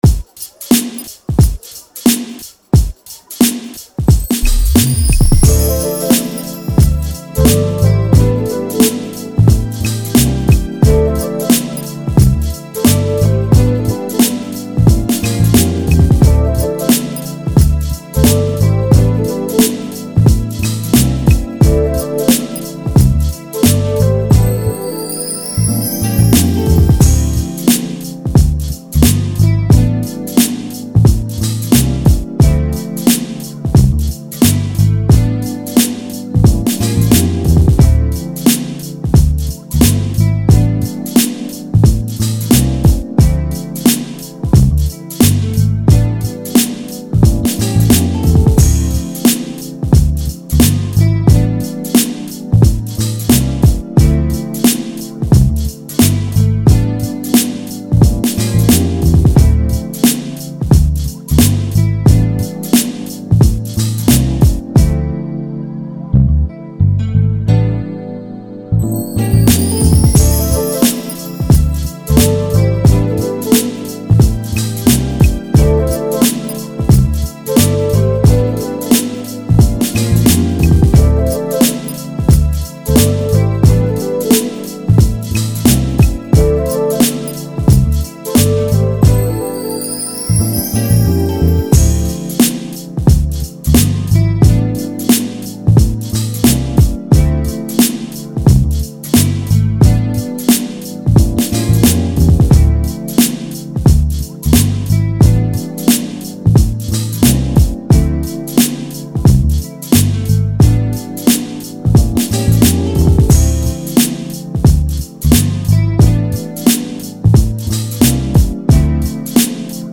90s, Hip Hop
A min